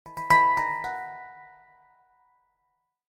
Samsung Galaxy Bildirim Sesleri - Dijital Eşik
Hang Drum
hang-drum.mp3